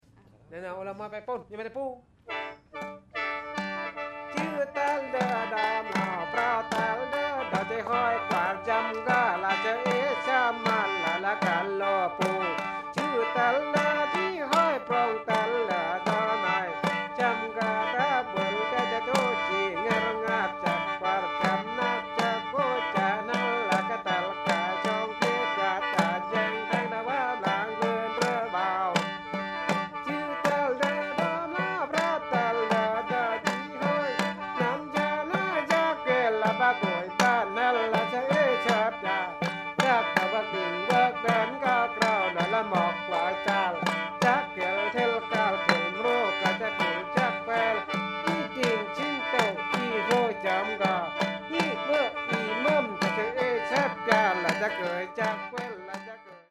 dance song the singers and sticks are joined by a Thai khene (free reed pipes) which the player heard when he was working for a Hmong family from Laos
The songs I have heard have a strong rapid rhythm and are reminiscent of the music of the Issaan or Laos.